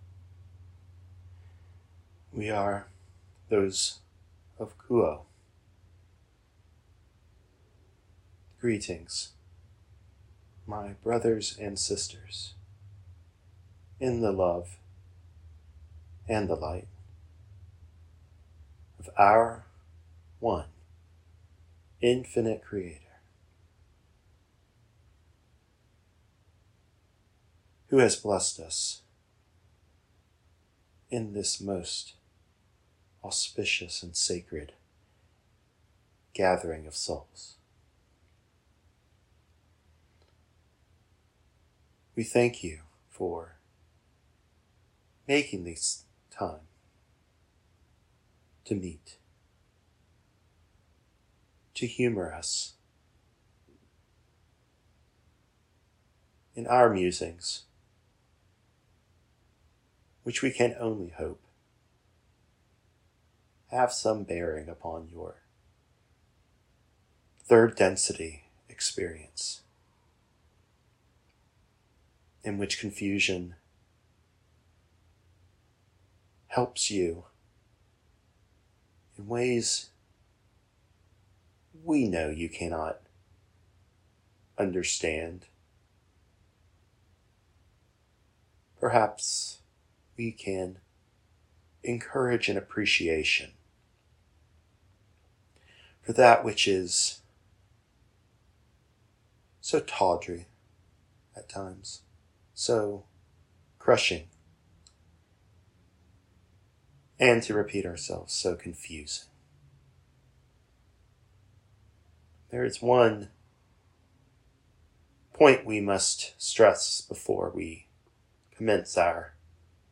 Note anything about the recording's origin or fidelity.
In this session from the Richmond Meditation Circle, Q’uo addresses issues arising from acceptance of struggles in life. We learn most from our attempts to overcome and oppose obstacles, because this effort exposes clues about our deeper identities.